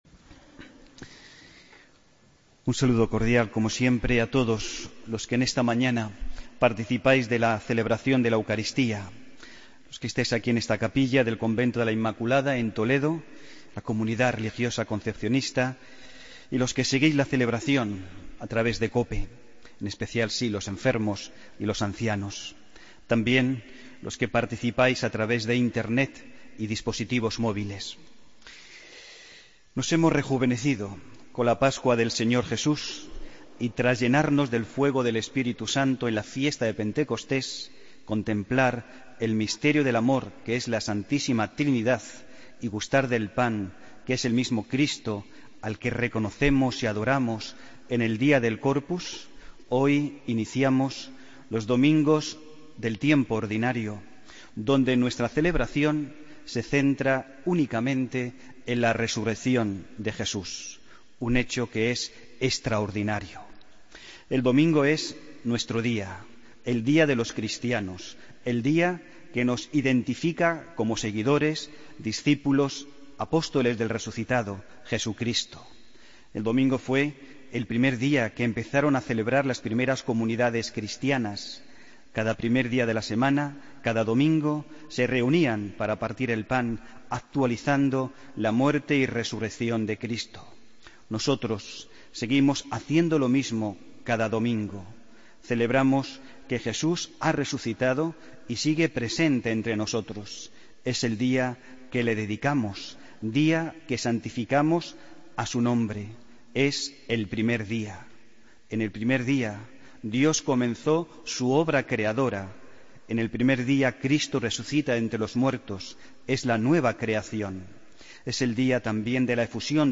Homilía del domingo 5 de junio de 2016